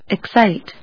音節ex・cite 発音記号・読み方
/eksάɪt(米国英語)/